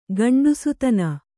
♪ gaṇḍusutana